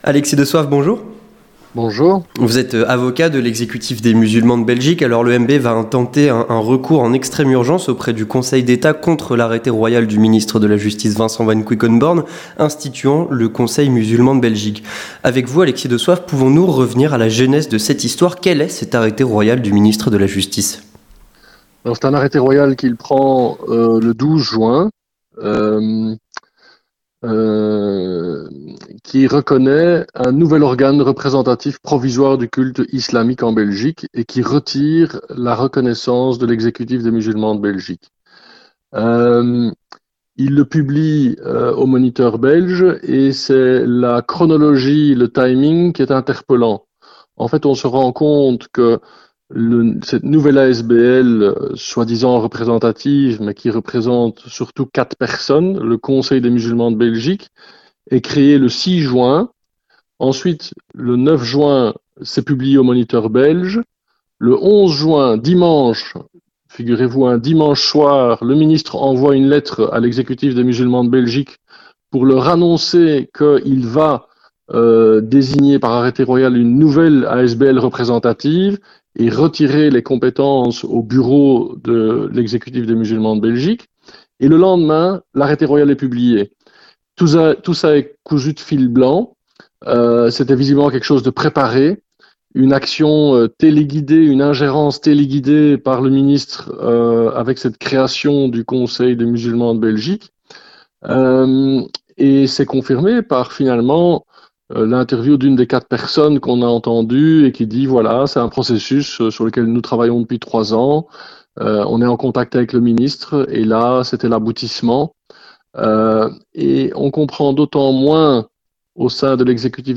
Entretien du 18h - L’Exécutif des Musulmans de Belgique lance un recours en extrême urgence auprès du Conseil d’Etat